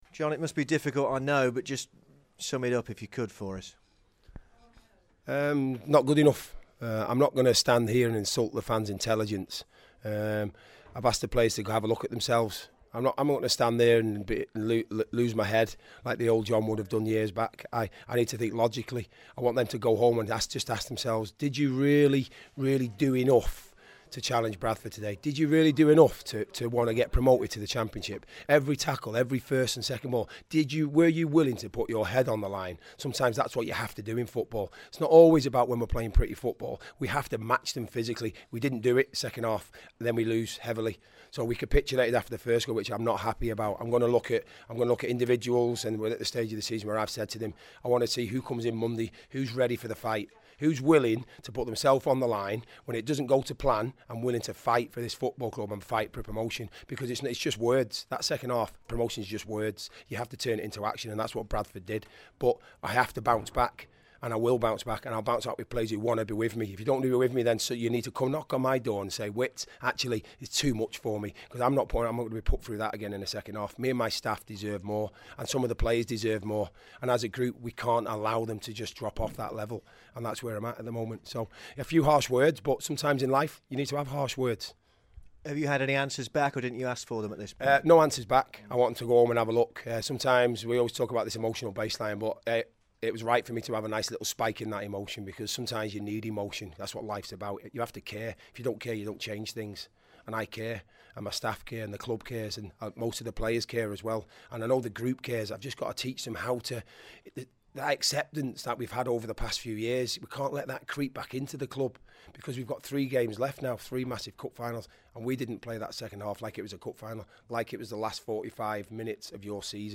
speaks to BBC WM after the 4-0 defeat to Bradford